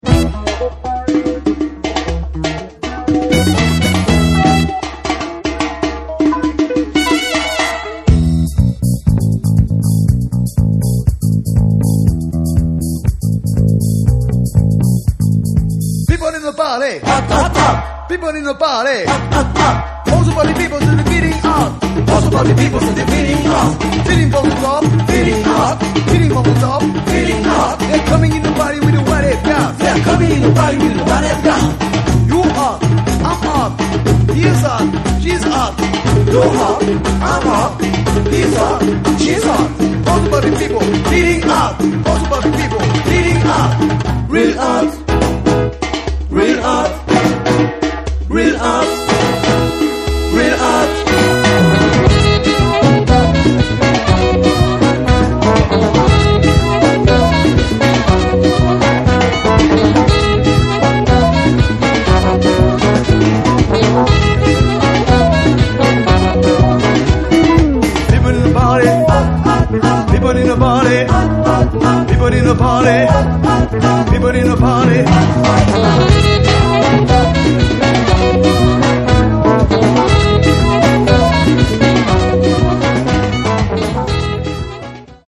Studio (NTP) Zouk 833 ko 01:25